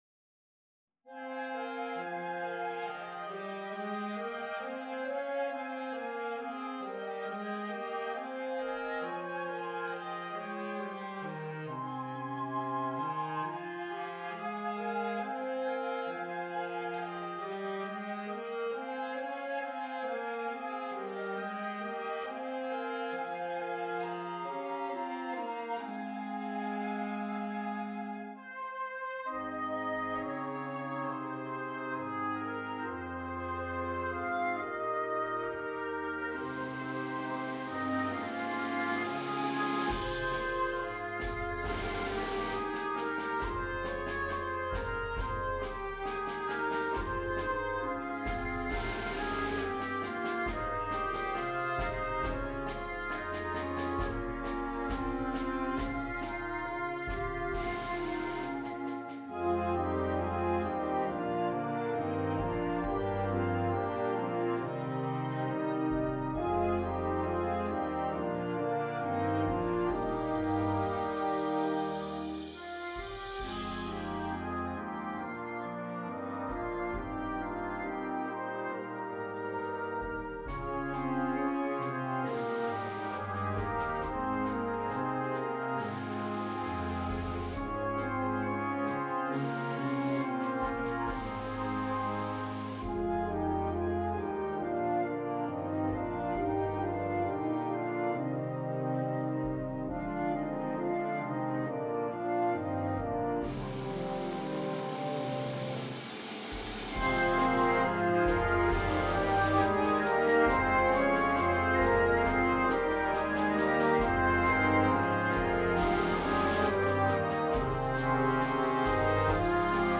At the end you can “hear” the change in season.